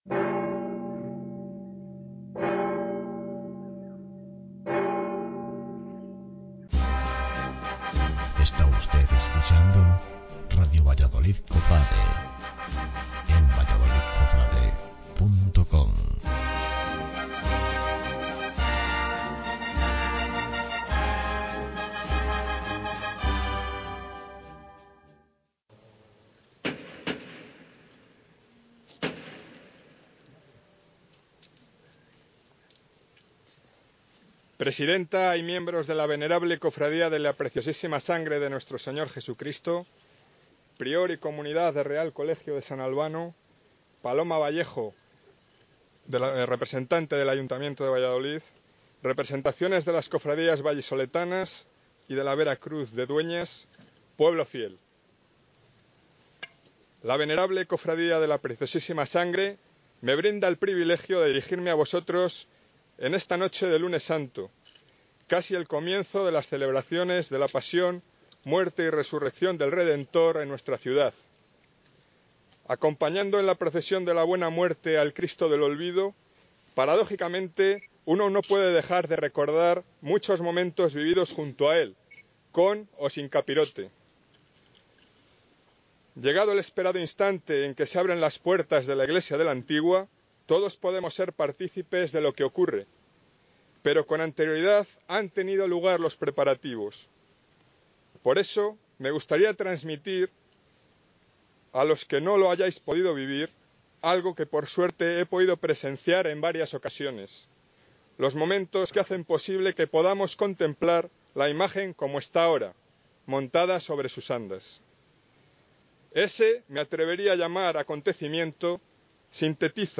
25 de Marzo - Lunes Santo